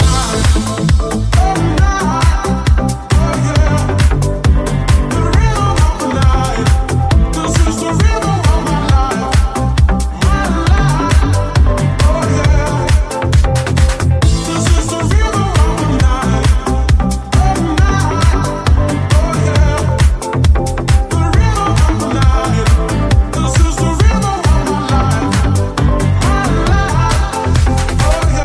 Genere: deep, house, club, remix